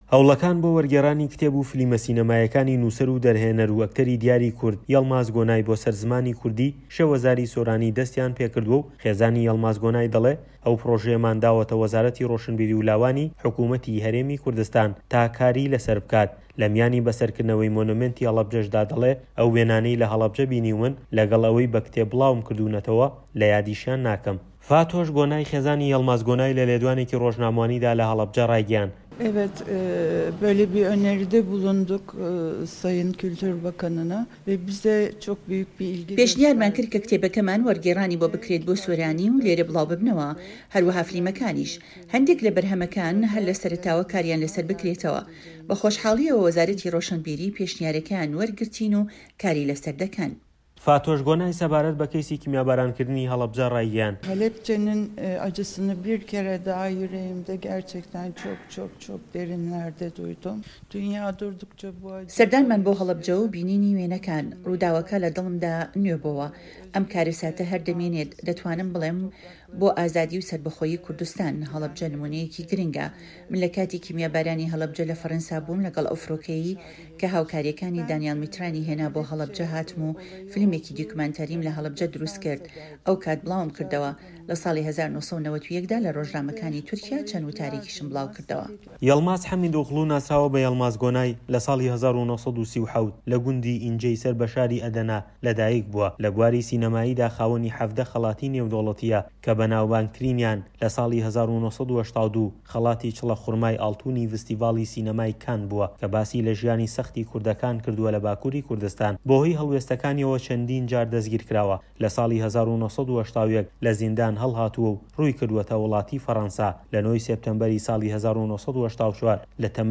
راپۆرتی پەیامنێر